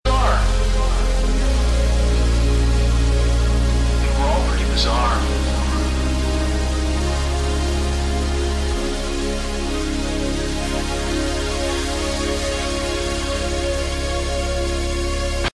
Category: Melodic Metal
vocals
guitars, keyboards
drums
guitars, backing vocals